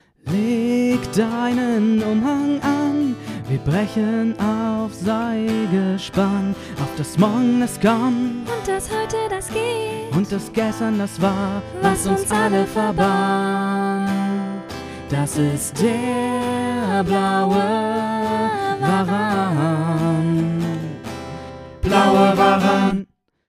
Das Intro zum Hörspiel "Der Blaue Waran".